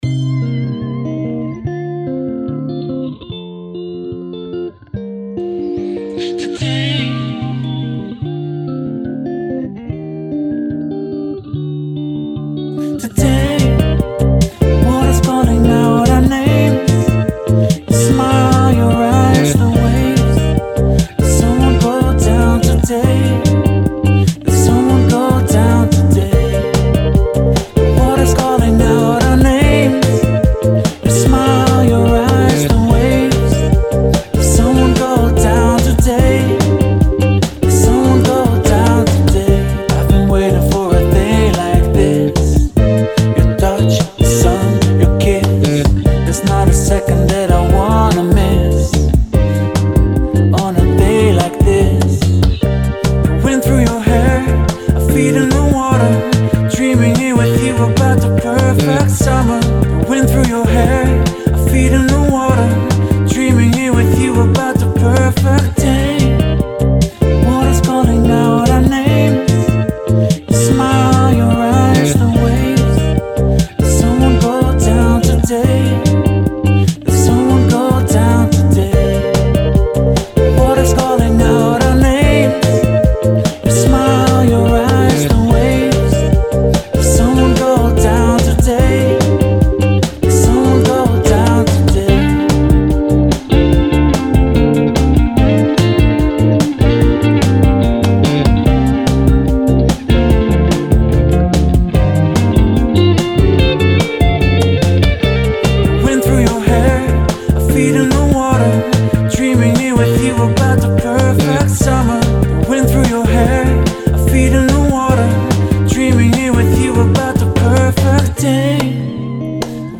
Genre: Indie Pop.